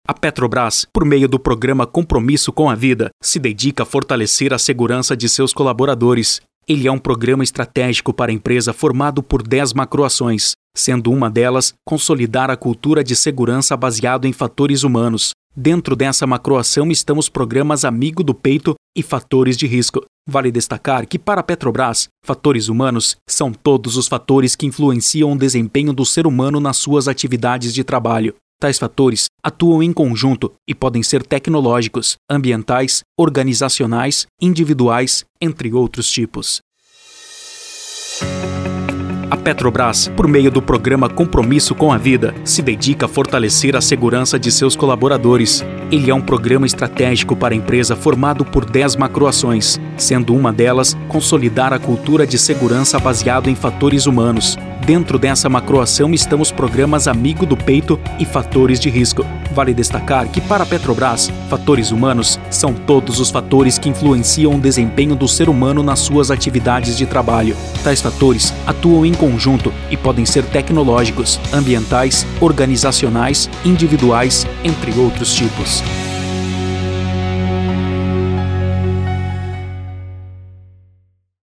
DEMONSTRATIVO INSTITUCIONAL: